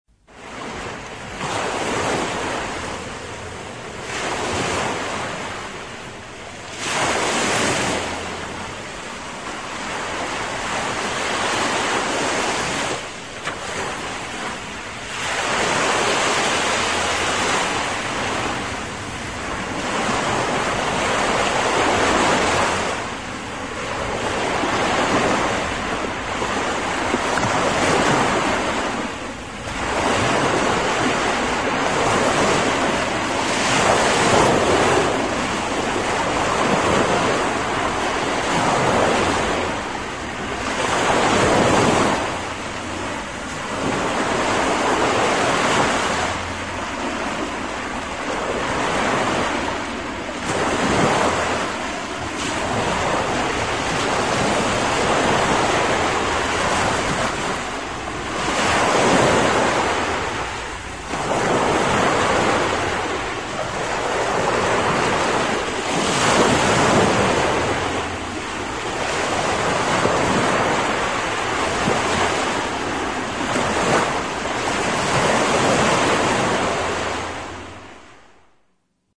OLASSEA24 - Tono movil - EFECTOS DE SONIDO
Tonos gratis para tu telefono – NUEVOS EFECTOS DE SONIDO DE AMBIENTE de OLASSEA24